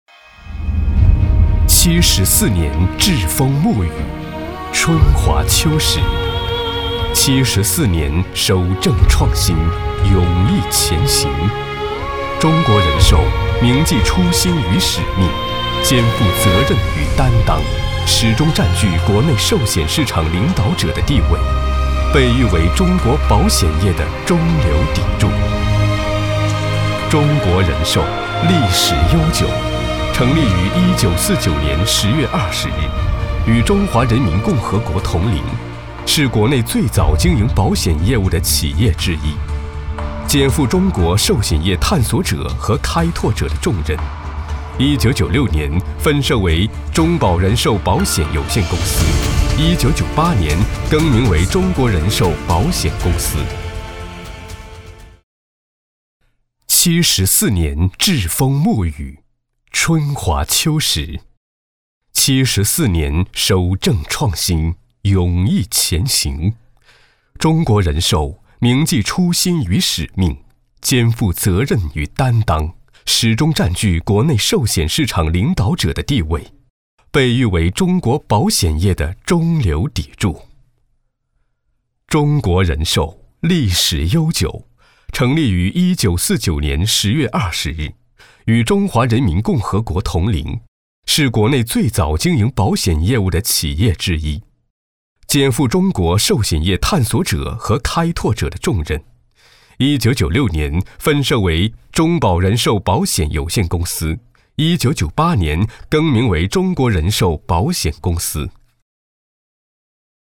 新生代配音员，声音有磁性，适合录制普通专题片稿件。